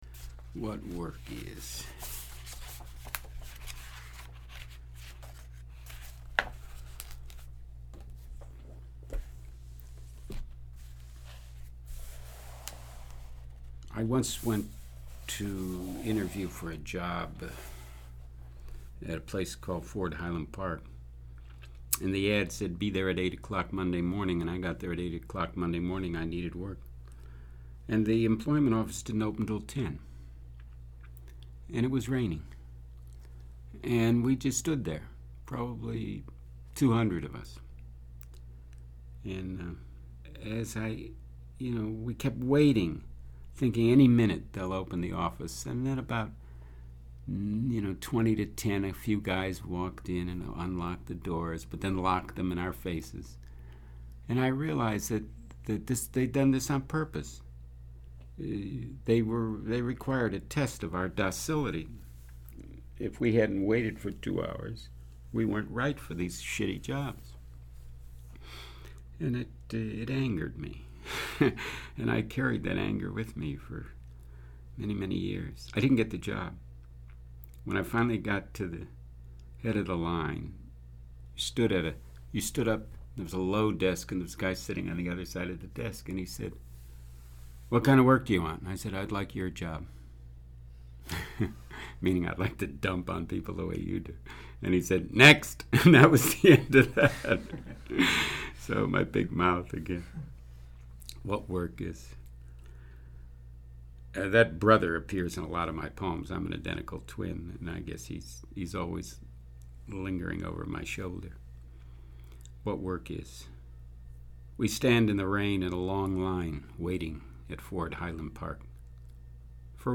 Â When you hear him read, he often shares a story about the poem – indeed, these succinct autobiographical narratives would make for a great collection of prose (were he so inclined). Â So, here’s a recording of him reading “What Work Is,” including one of those introductions (he starts speaking at around 12 seconds in):
He’s a wonderful reader of his own work.